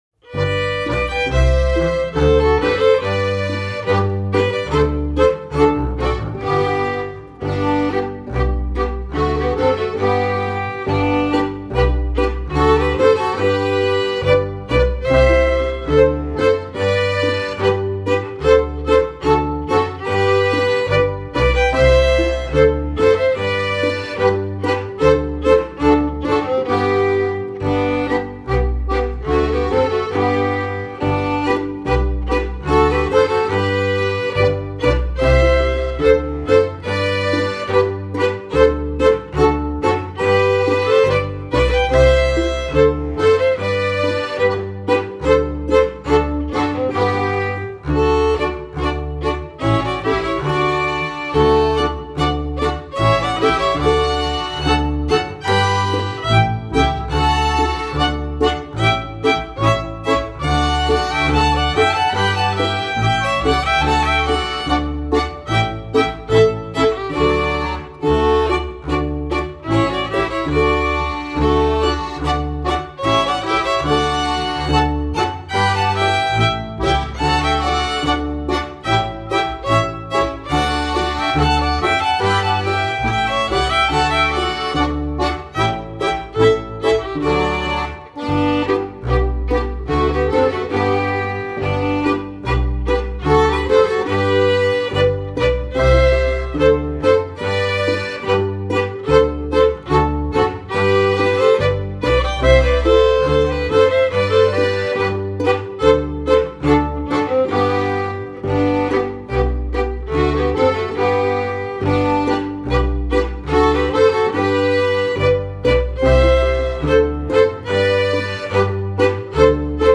Rundtänze wie Walzer, Polka, Marsch, Mazurka oder Boarischer legt die Musik genauso auf wie einfache Volkstänze: beispielsweise Jägermarsch, Hiatamadl oder Siebenschritt.